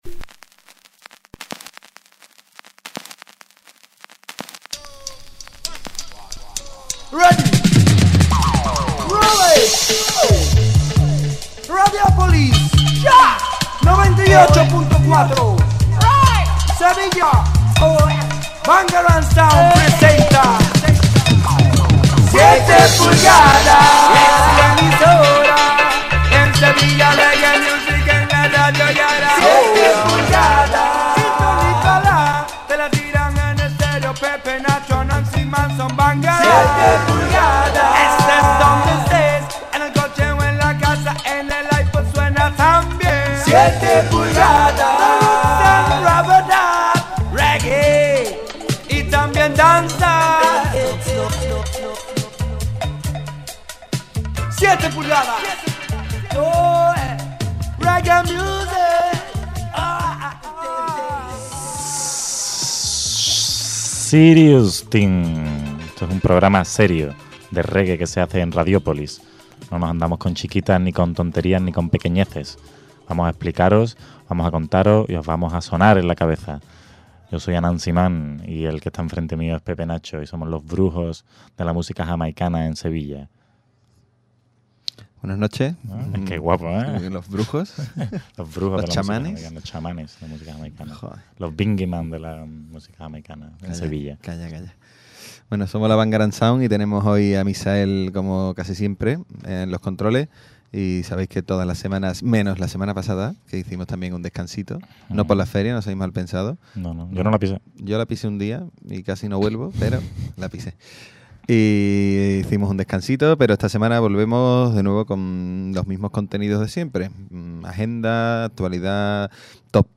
7PULGADAS es un programa dedicado a la música reggae y dancehall dirigido y presentado por la BANGARANG SOUND